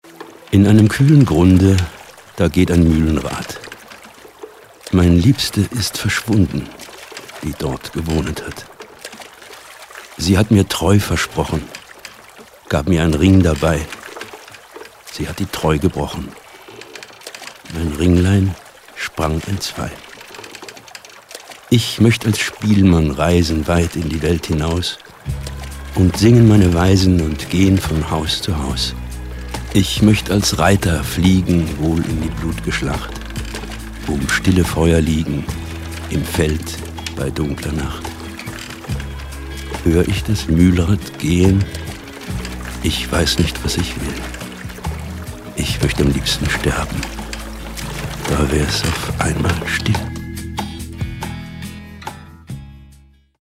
Doch die Kunst und das Handwerk, mit seiner wundervollen Stimme umzugehen, machte die sagenhafte Synchronkarriere von Klaus Kindler aus.
H Ö R B E I S P I E L E – in der finalen Tonmischung:
Gedicht 2: